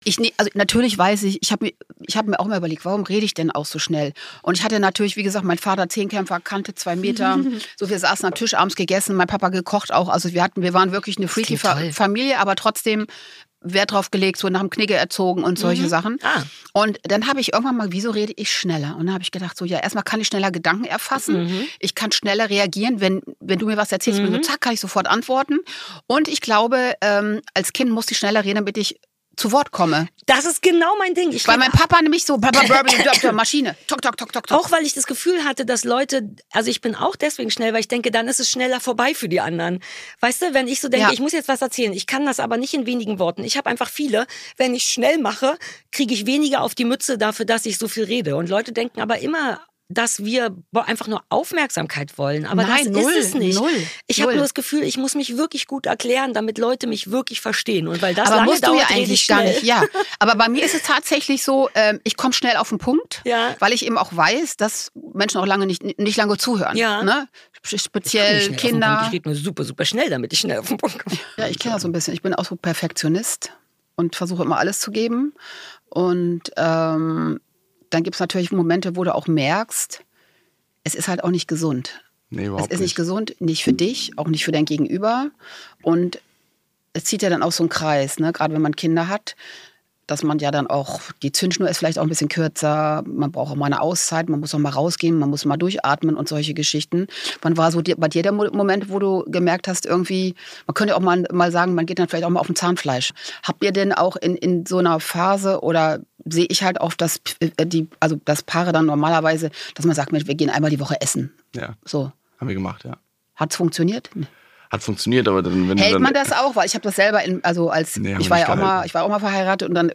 Das Interview-Format mit Natascha Ochsenknecht: wer Nataschas